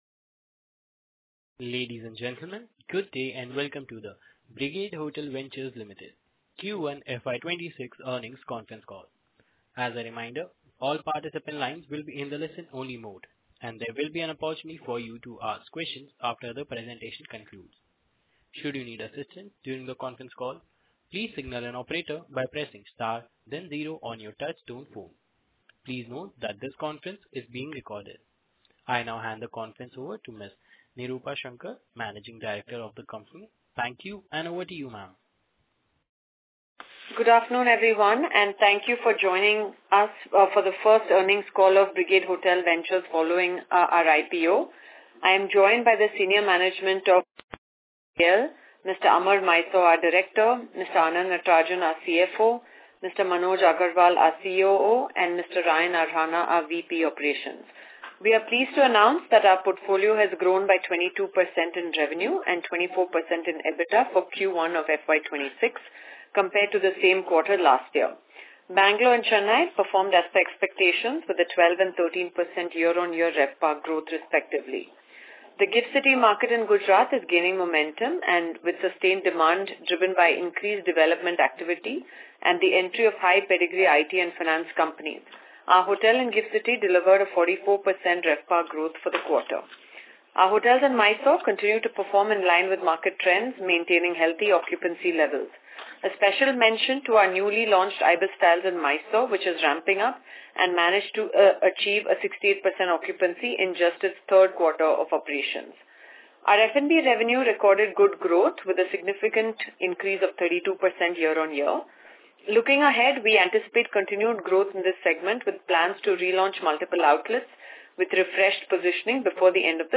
Audio or Video Recordings of Post Earnings / Quarterly Calls | Brigade Hotel Ventures Limited